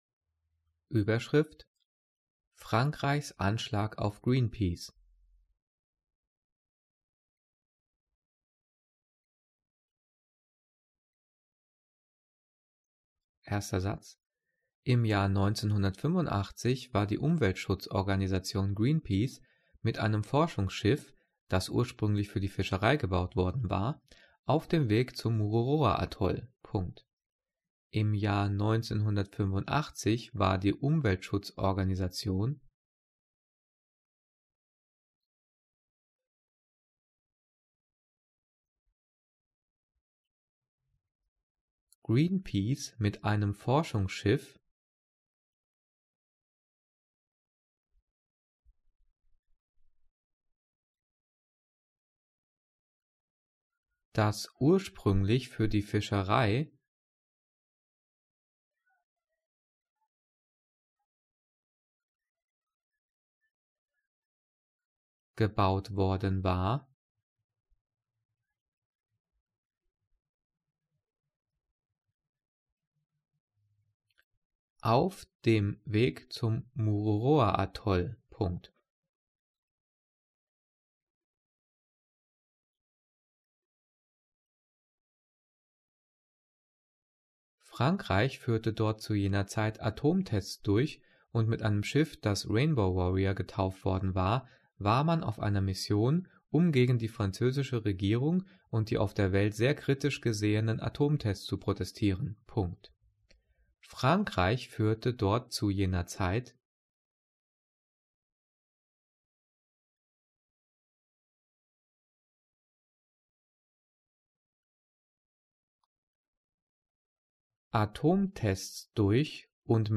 Übungsdiktat 'Frankreichs Anschlag auf Greenpeace' für die 9. und 10. Klasse zum Thema Zeichensetzung diktiert und mit Lösung.
Die vielen Sprechpausen sind dafür da, dass du die Audio-Datei pausierst, um mitzukommen.